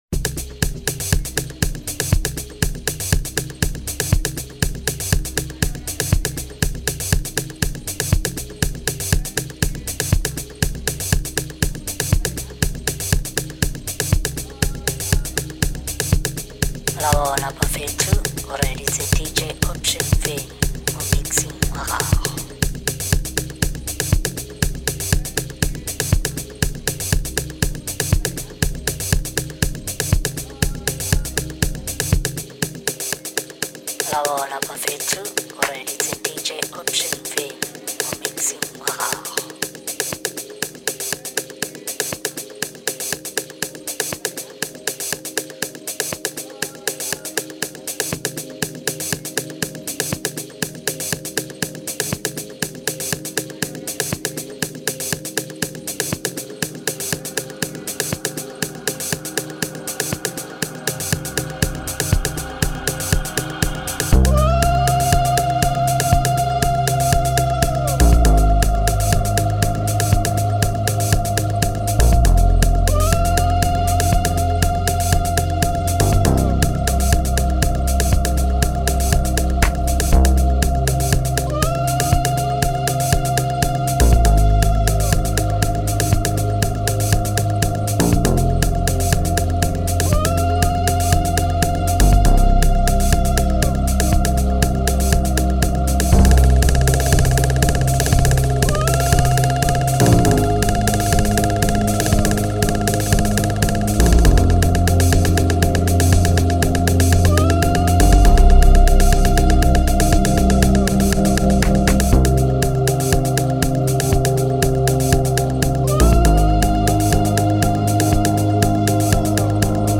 30:35 Genre : Local House Size